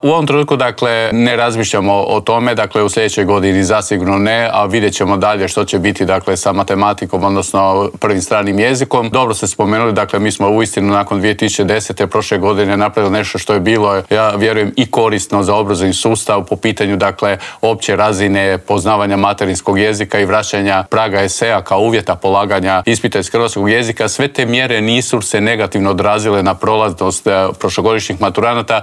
ZAGREB - U Intervjuu tjedna Media servisa razgovarali smo s ravnateljem Nacionalnog centra za vanjsko vrednovanje obrazovanja, Vinkom Filipovićem.